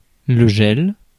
Ääntäminen
Synonyymit givre sanctuarisation Ääntäminen France Tuntematon aksentti: IPA: /ʒɛl/ Haettu sana löytyi näillä lähdekielillä: ranska Käännös Substantiivit 1. jää Muut/tuntemattomat 2. hall 3. külm Suku: m .